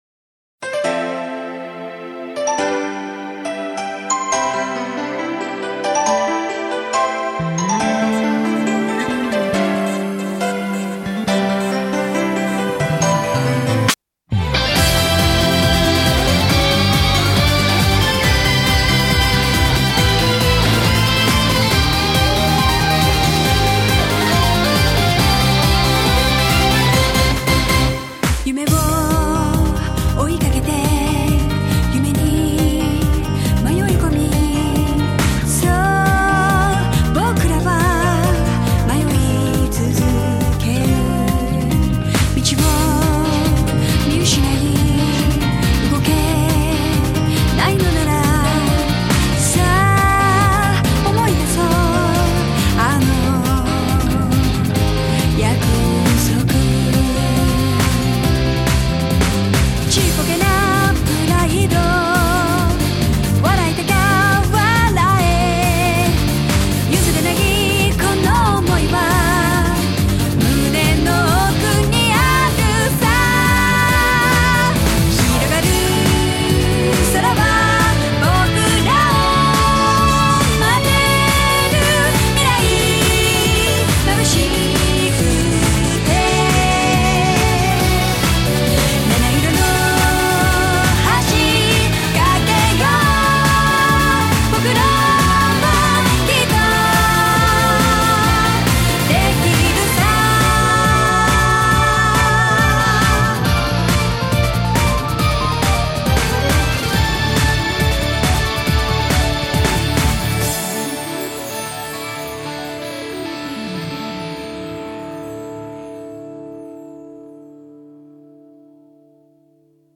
주제곡!!